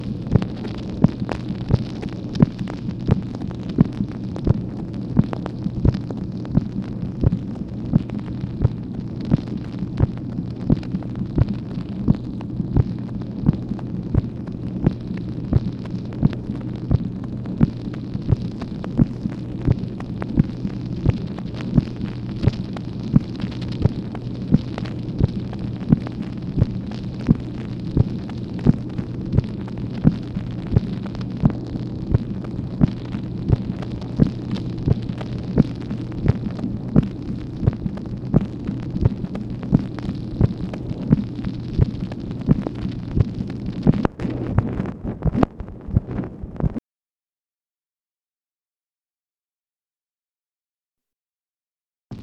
MACHINE NOISE, June 28, 1965
Secret White House Tapes | Lyndon B. Johnson Presidency